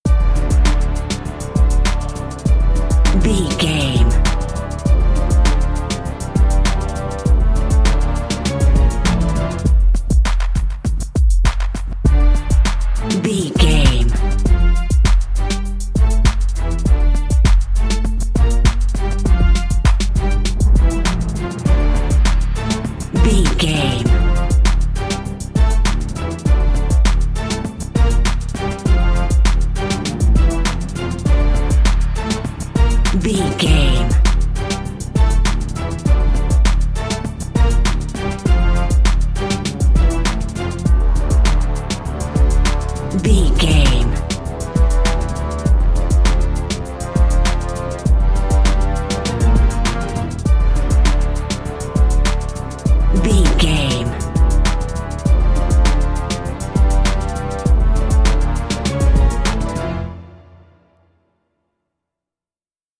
Aeolian/Minor
D
strings
drums
brass
drum machine
hip hop
soul
Funk
acid jazz
confident
energetic
bouncy
Triumphant
funky